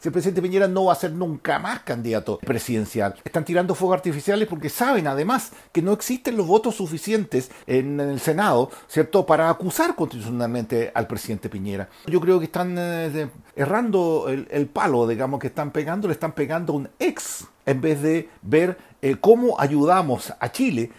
Al respecto, en Chile Vamos el diputado de RN, Miguel Mellado, dijo que son sólo “fuegos artificiales” tomando en cuenta que el actual mandatario no volverá al mundo político.